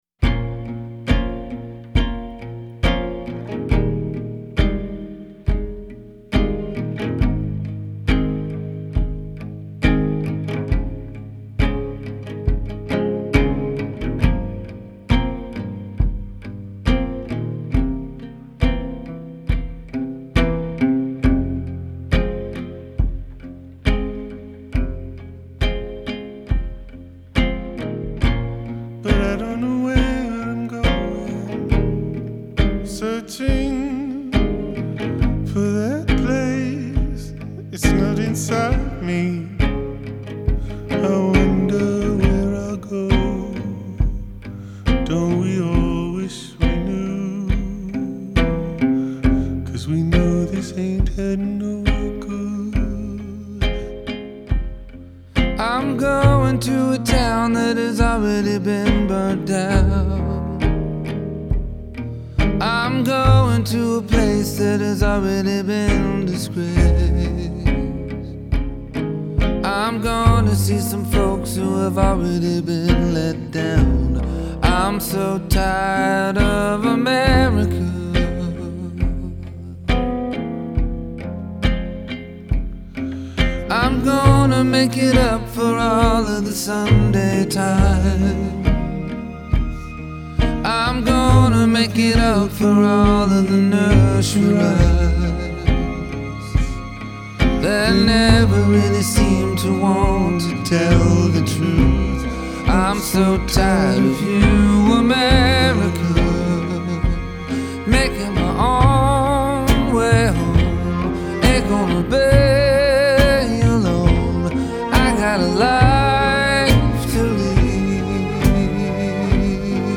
Genre : Folk